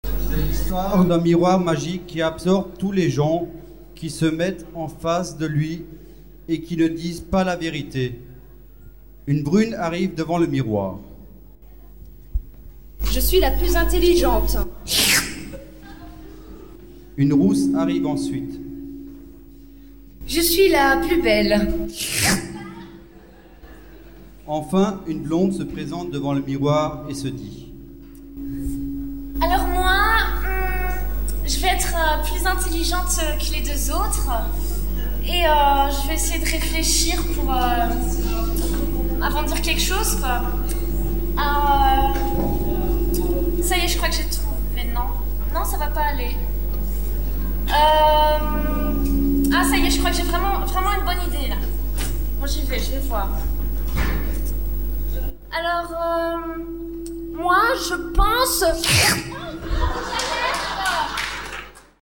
Soirée de l'amitié 2001 Photos
Sketch